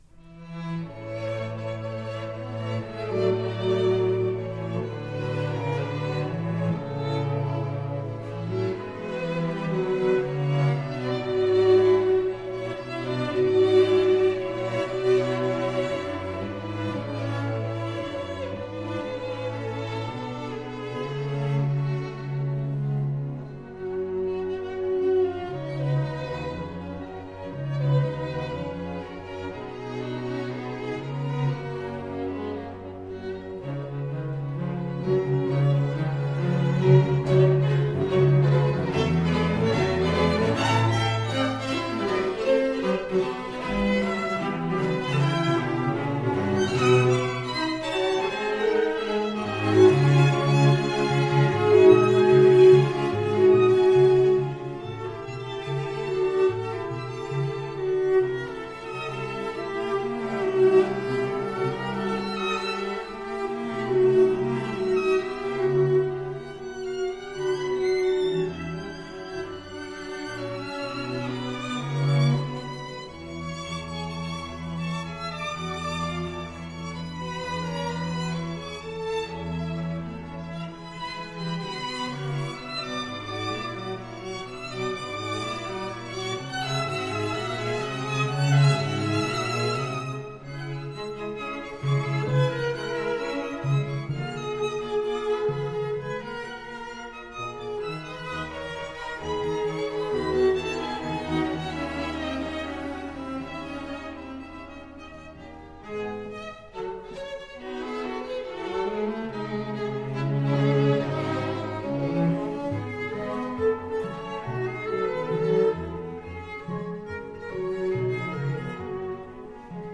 Instrument: String Sextet
Style: Classical
Audio: Boston - Isabella Stewart Gardner Museum
Audio: Musicians from Marlboro (ensemble)
string-sextet-op-48.mp3